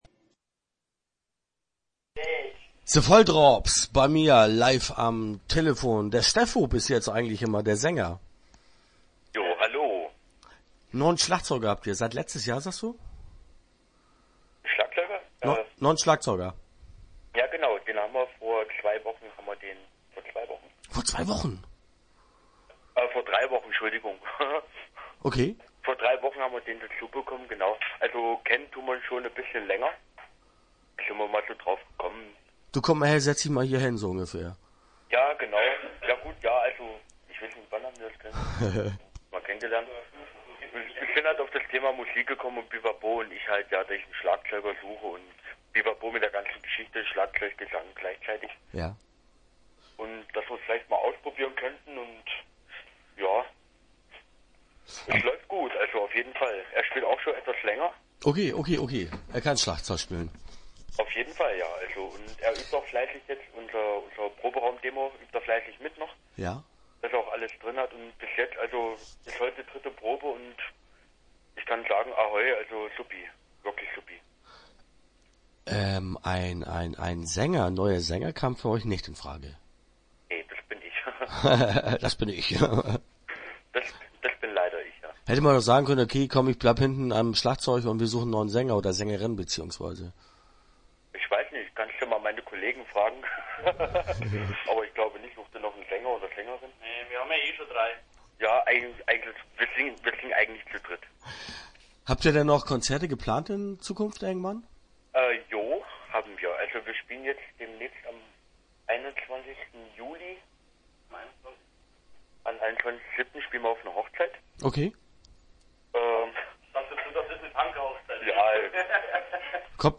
Start » Interviews » The Volldrops